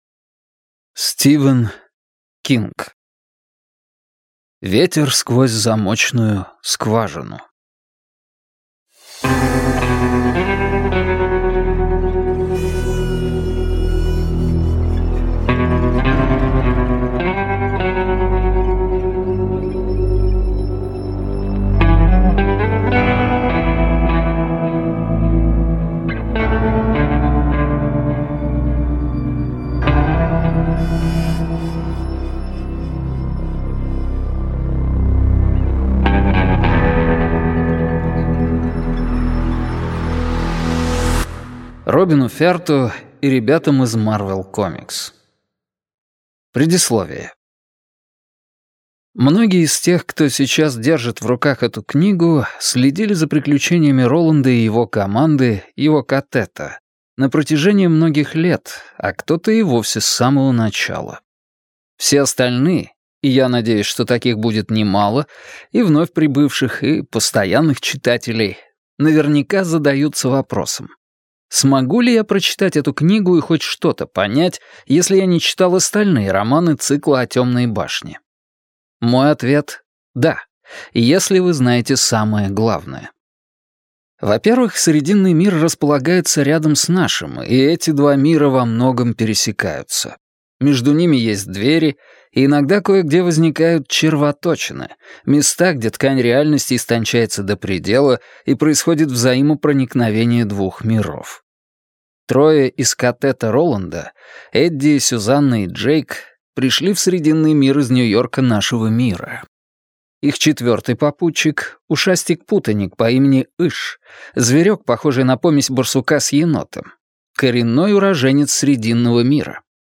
Аудиокнига Ветер сквозь замочную скважину - купить, скачать и слушать онлайн | КнигоПоиск